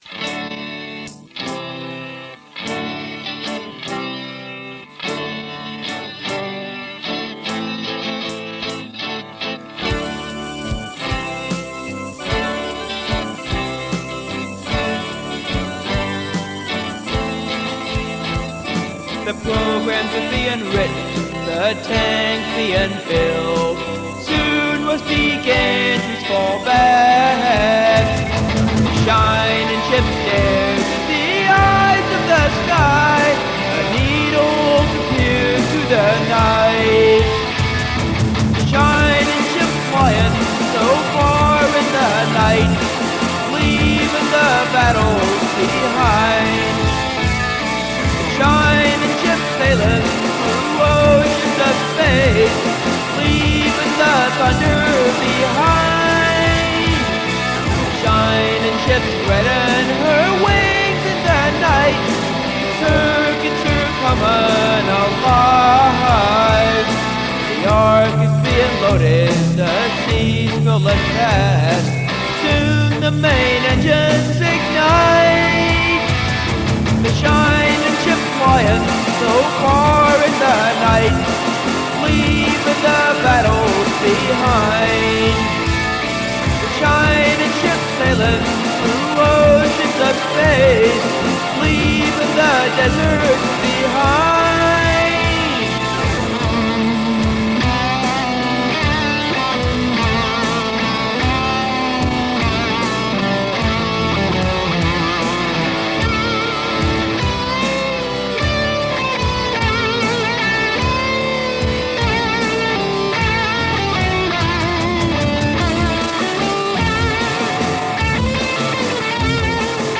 This is "Silver Ship" about people (now understood to be only the rich) fleeing a dying Earth. Possibly my best guitar work ever
Nice guitar work!
That recording was done with my own modified amp and guitar too.
I put it in my bedroom, mic'ed it, and ran the microphone and guitar cables under the door, which I then closed.
Amp was a 50 watt marshall that had been a rental. I added an effects loop, extra gain, and channel switching. All tubes as there was no good solid state metal amp in those days.